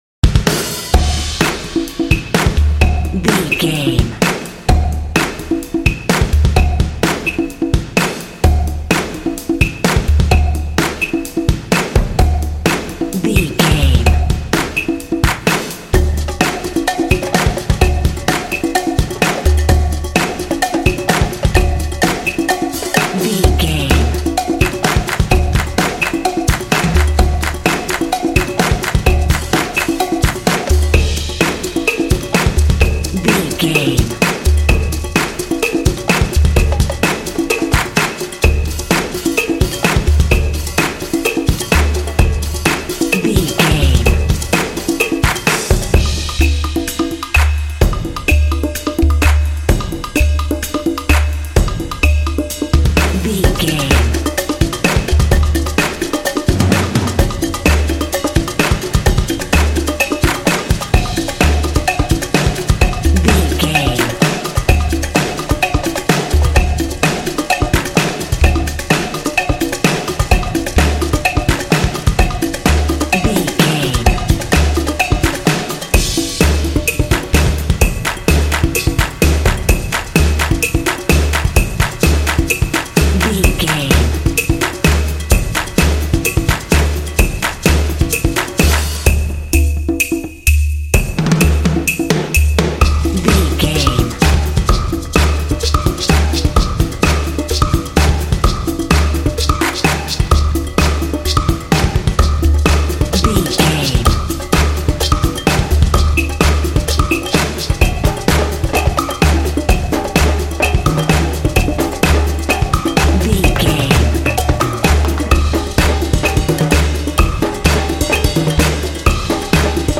Epic / Action
Atonal
groovy
intense
driving
energetic
lively
drums
percussion